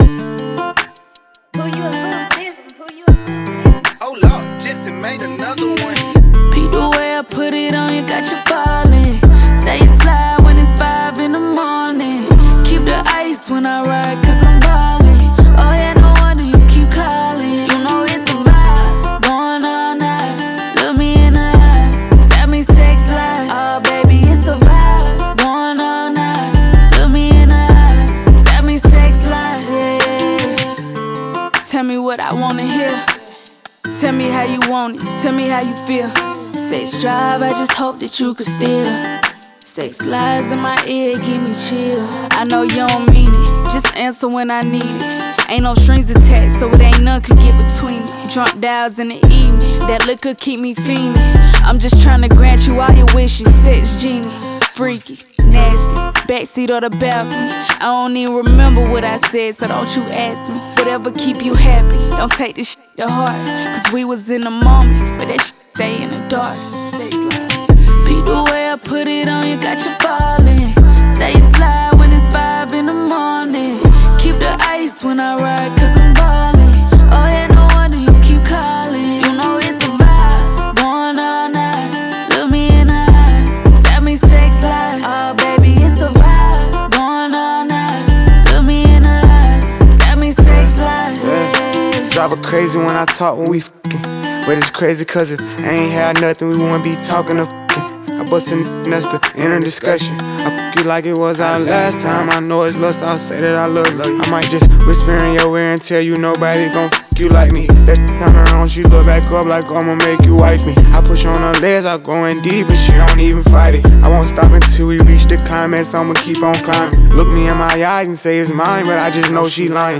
это трек в жанре хип-хоп